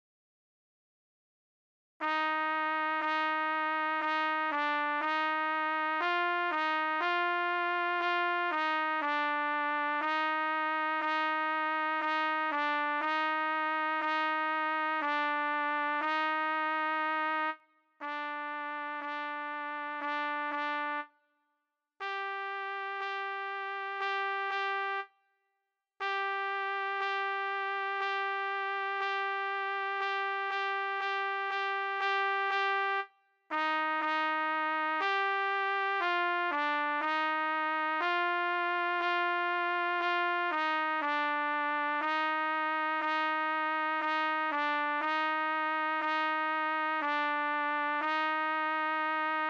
C=Harmony/Bass Part-for beginner players